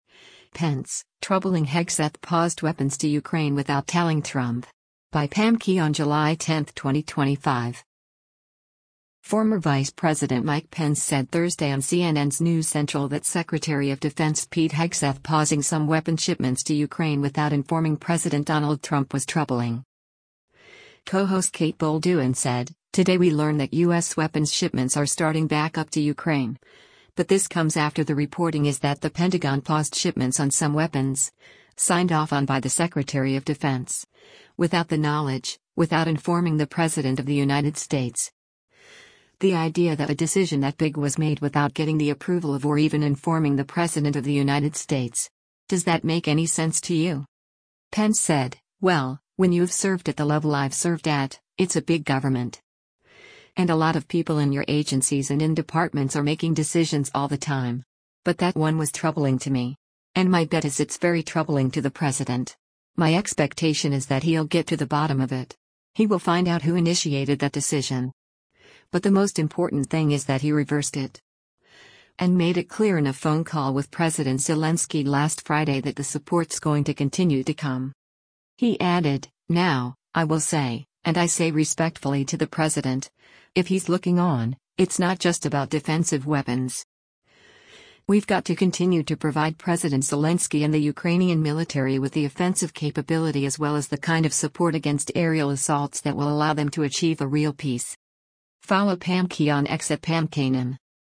Former Vice President Mike Pence said Thursday on CNN’s “News Central” that Secretary of Defense Pete Hegseth pausing some weapon shipments to Ukraine without informing President Donald Trump was “troubling.”